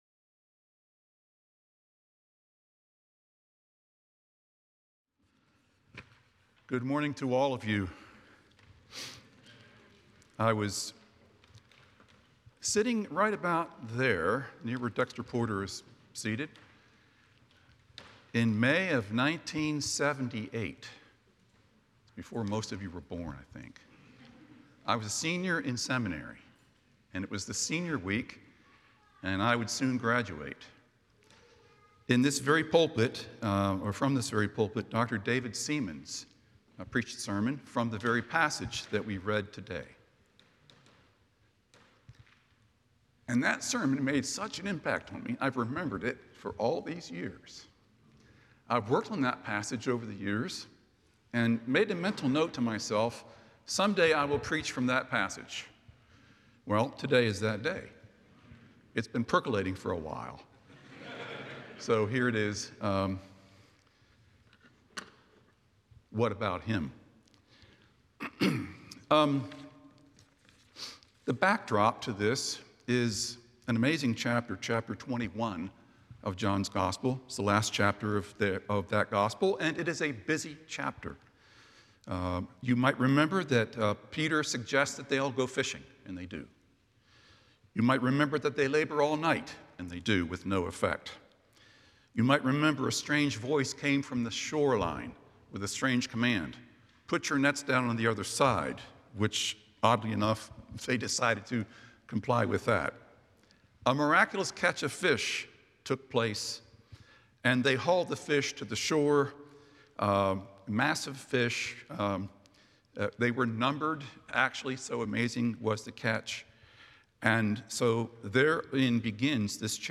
The following service took place on Wednesday, October 23, 2024.